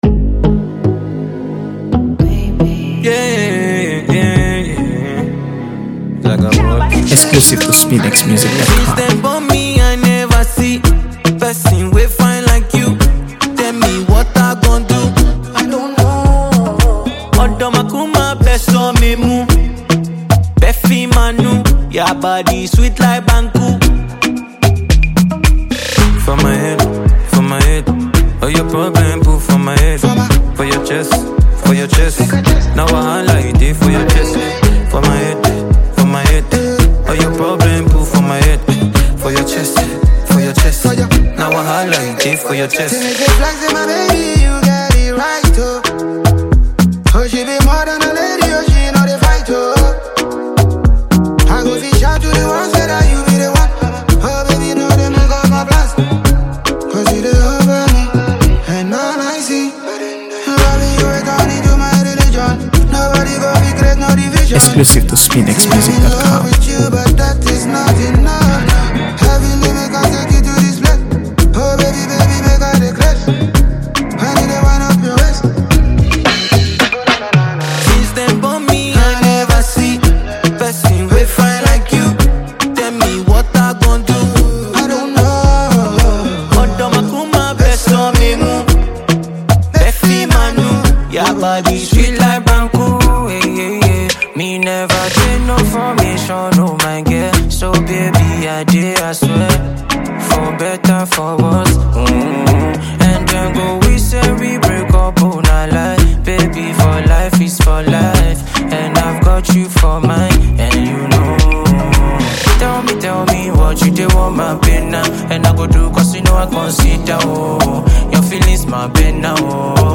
AfroBeats | AfroBeats songs
captivating blend of smooth rhythms and rich vocals